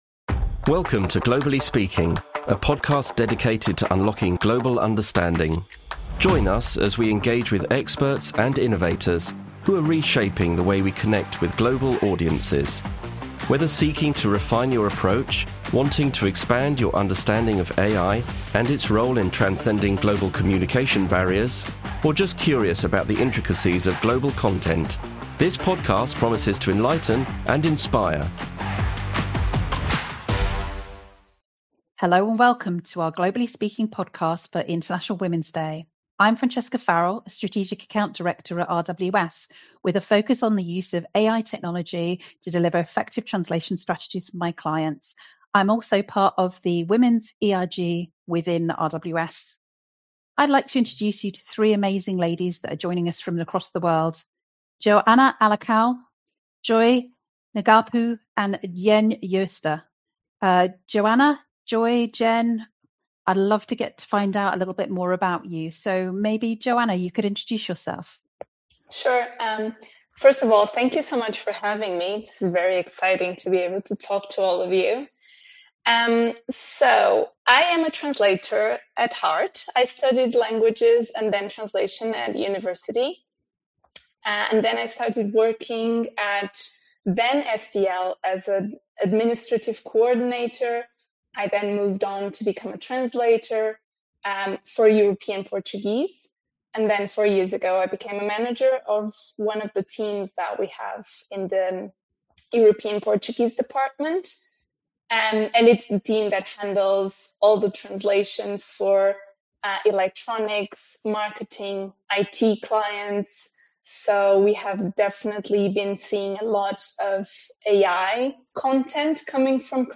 This International Women’s Day, three amazing women working with AI, language, and culture in RWS offices around the world reveal how human expertise is what makes AI truly work for authentic global connections.